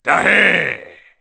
Line of Klump in Donkey Kong: Barrel Blast.